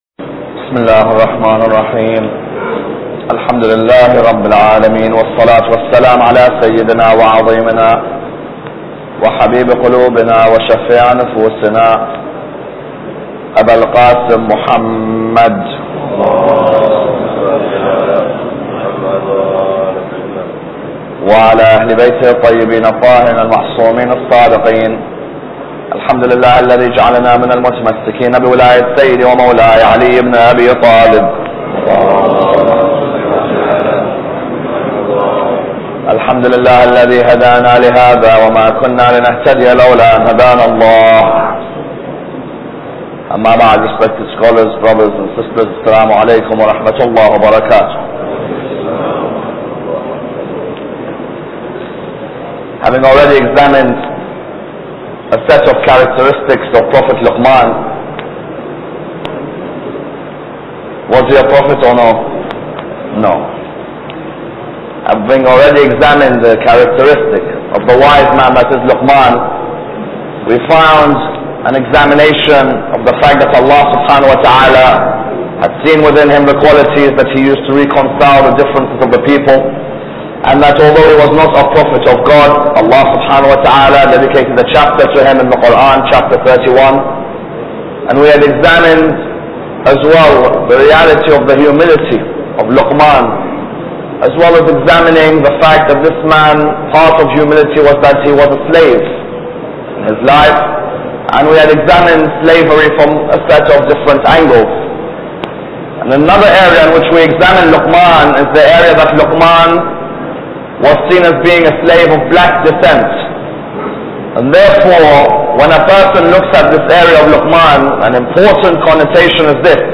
Lecture 4